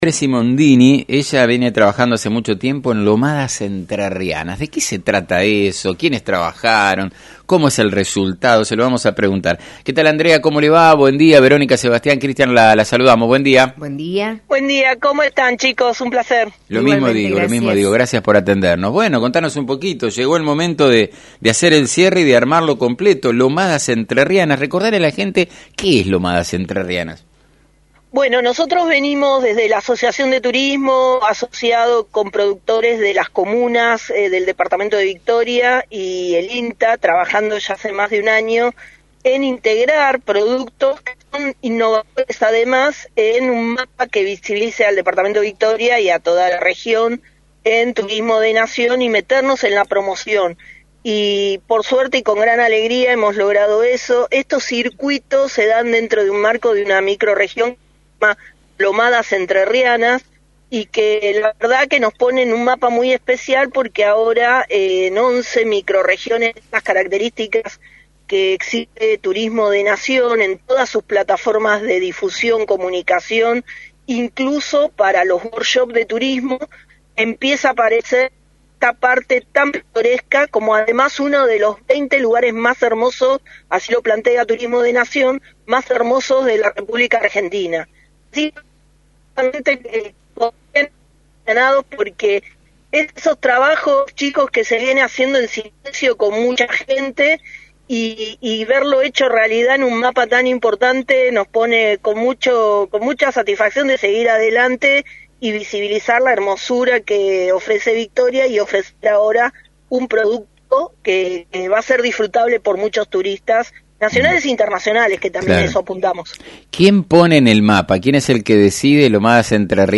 por FM90.3